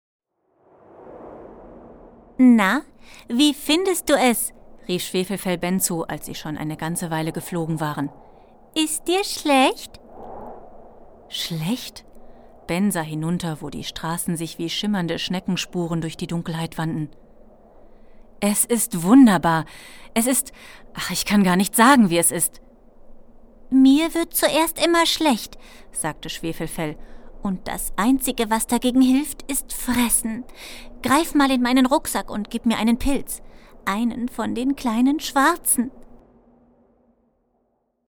Sprechprobe: Industrie (Muttersprache):
german female voice over talent